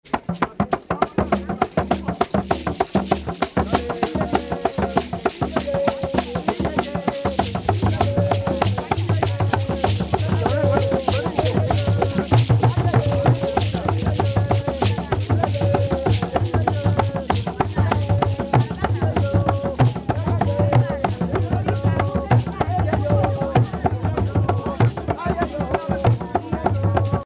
danse
Pièce musicale éditée